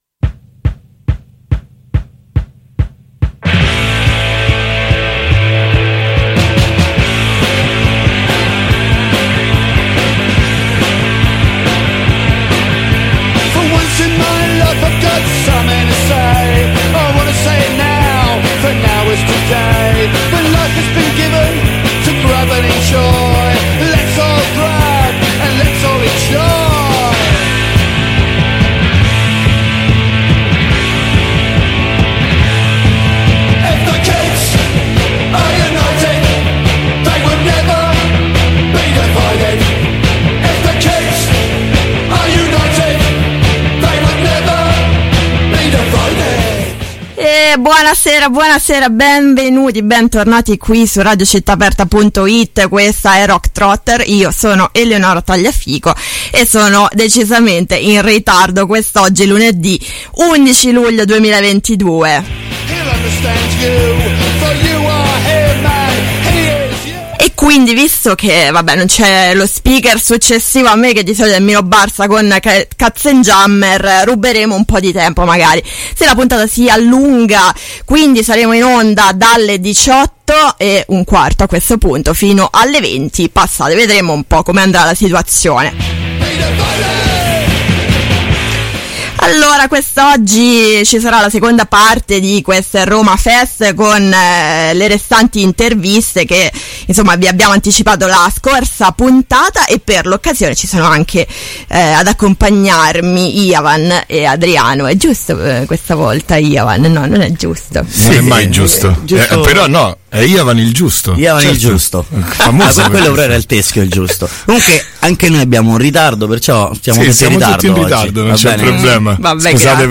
Seconda puntata dello speciale dedicato a ‘Questa è Roma’ Fest, tenutosi a Parco Schuster sabato 25 giugno 2022.
Ci sono state le interviste a: Antidigos, Sud Disorder, No More Lies, Bloody Riot.